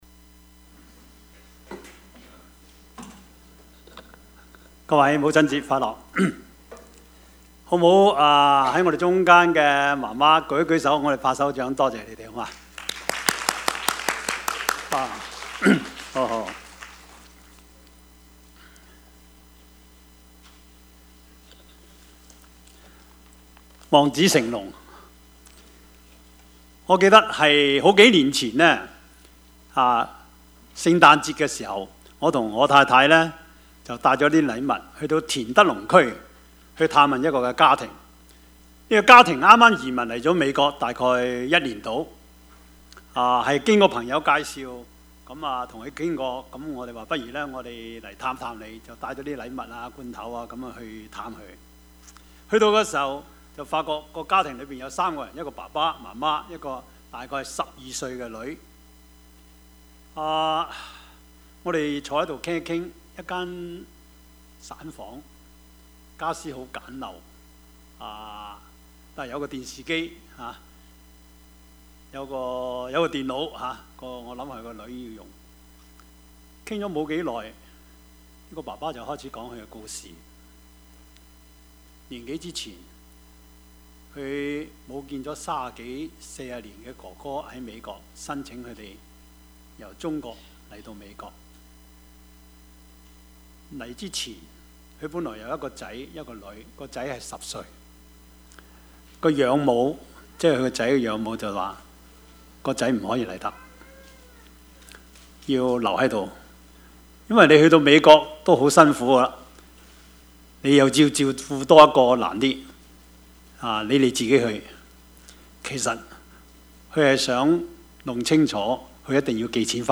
Service Type: 主日崇拜
Topics: 主日證道 « 你們要休息 蔣介石(一) »